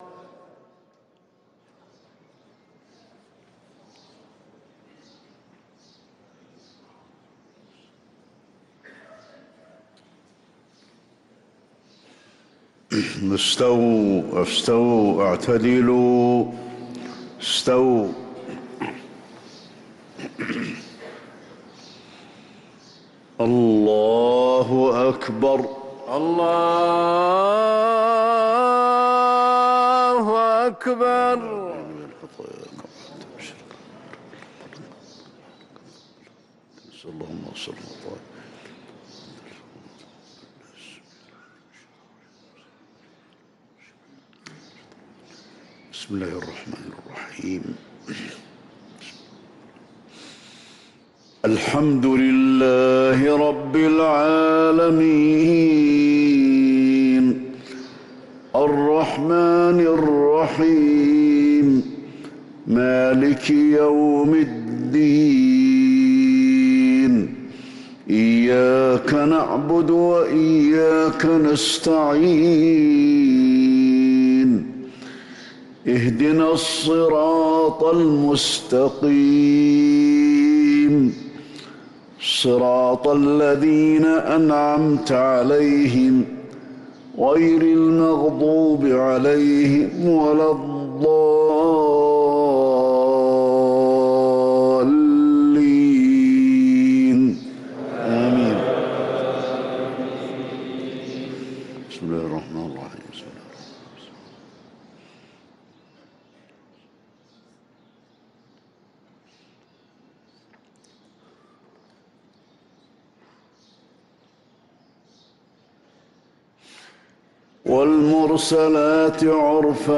صلاة الفجر للقارئ علي الحذيفي 25 ربيع الأول 1445 هـ